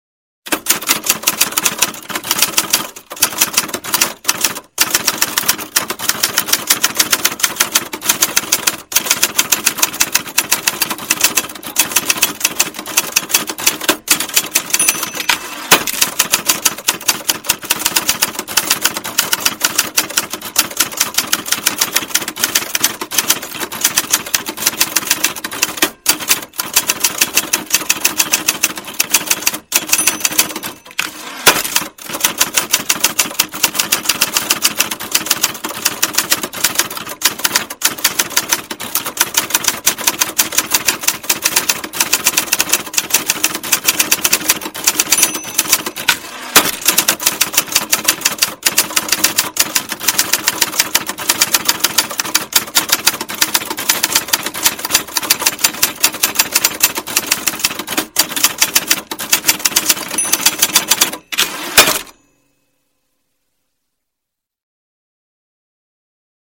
Здесь собраны характерные эффекты: от ритмичного стука клавиш до металлического звона каретки.
Громкий стук клавиш древней печатной машинки